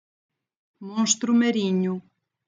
(pronunciação)